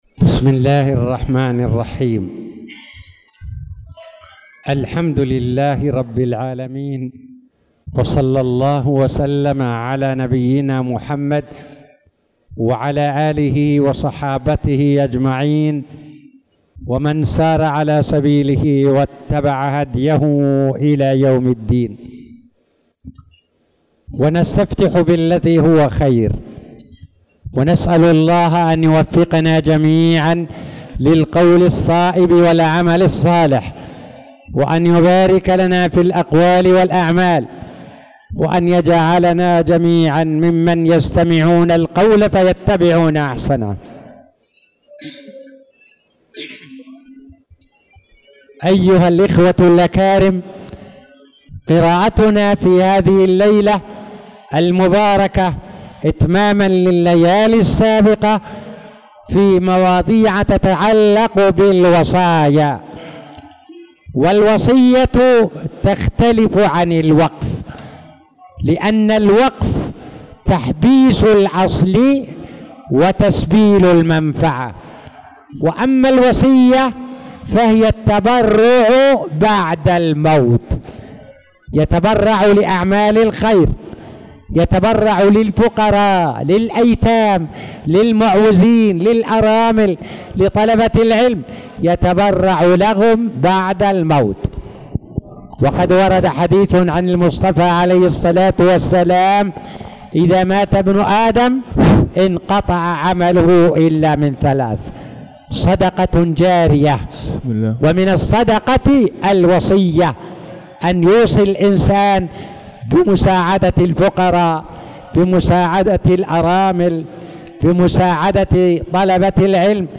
تاريخ النشر ٣ شعبان ١٤٣٧ هـ المكان: المسجد الحرام الشيخ